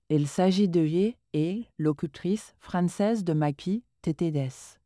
🔉FR-FR.Female.Female-1
FR-FR.Female.Female-1_MagpieTTS.wav